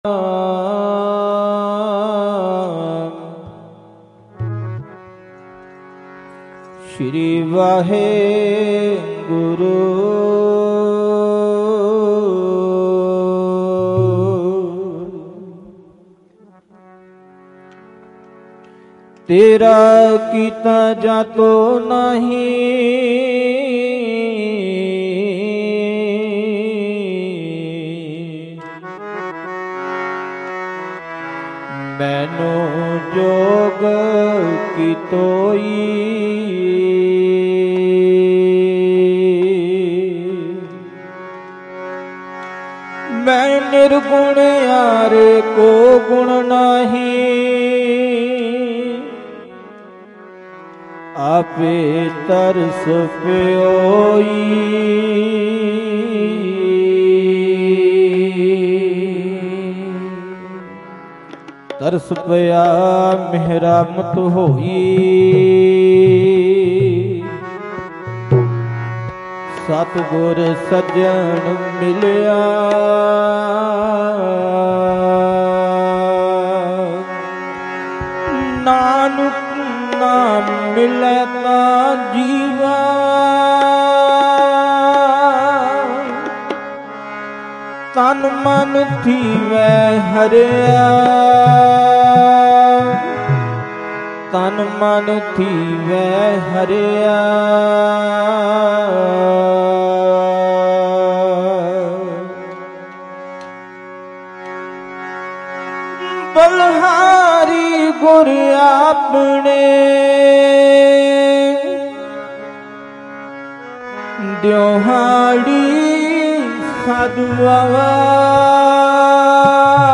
Live
Live Broadcast